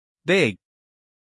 big-stop-us-male.mp3